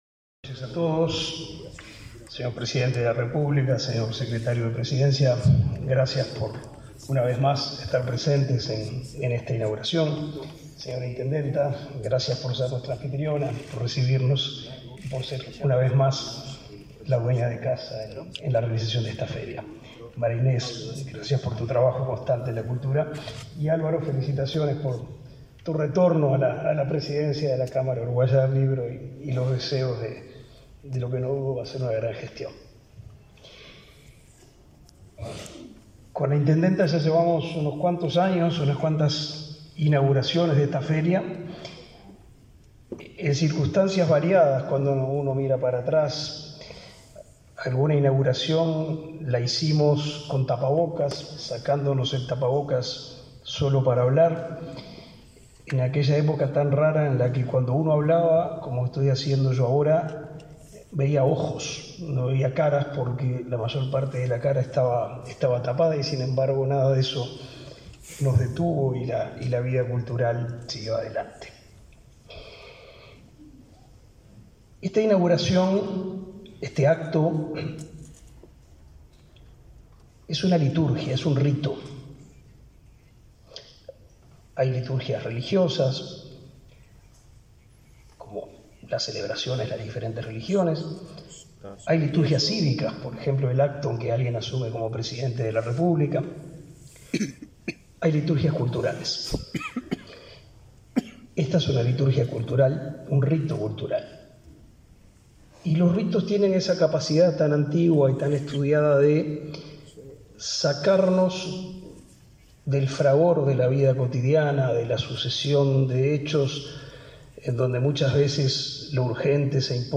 Palabras del ministro de Educación y Cultura, Pablo da Silveira
Palabras del ministro de Educación y Cultura, Pablo da Silveira 03/10/2023 Compartir Facebook X Copiar enlace WhatsApp LinkedIn Con la presencia del presidente de la República, Luis Lacalle Pou, se realizó, este 3 de octubre, la ceremonia Inaugural de la 45.ª Feria Internacional del Libro de Montevideo 2023. El ministro de Educación y Cultura, Pablo da Silveira, realizó el cierre del acto.